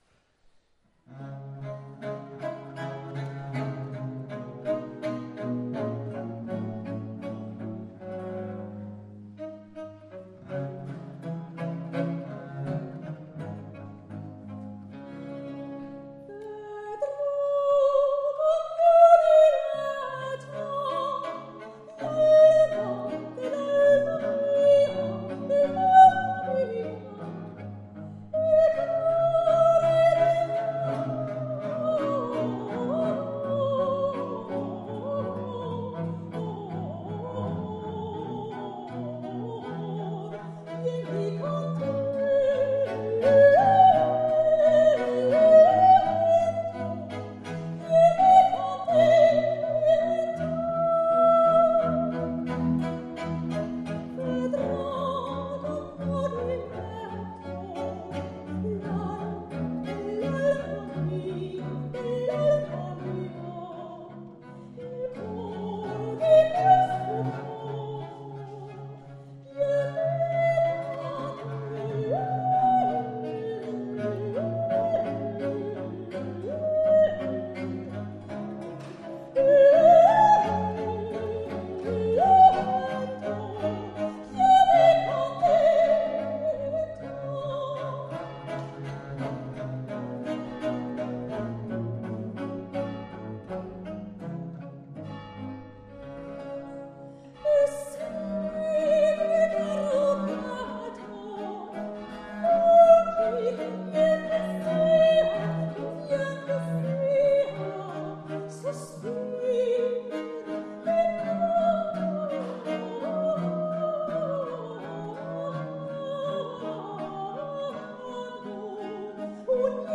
La musique baroque vivante !
Enregistré à l'église d'Arberats (64120) le 24 Juillet 2012